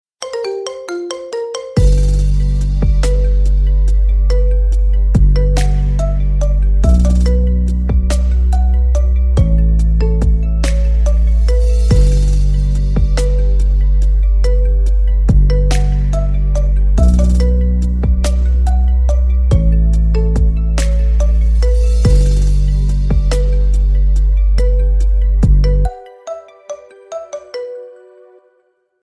# Рингтоны Без Слов
# Рингтоны Ремиксы